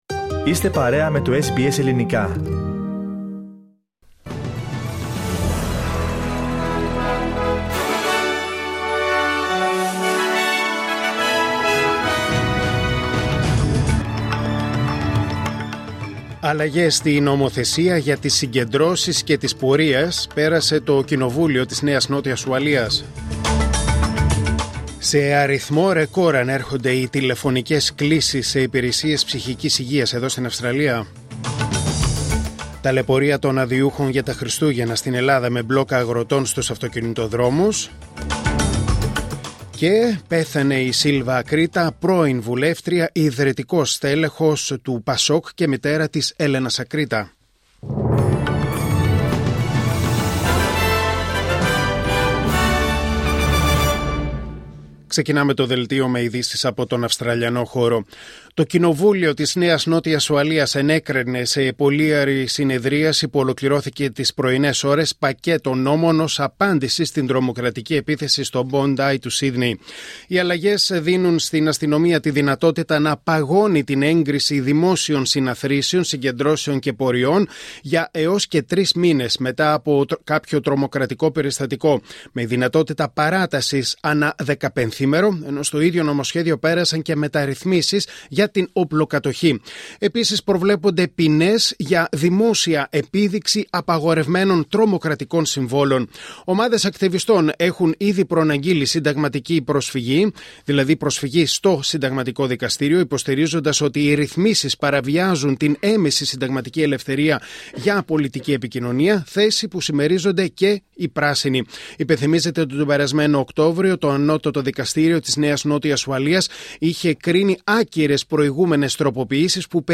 Δελτίο Ειδήσεων Τετάρτη 24 Δεκεμβρίου 2025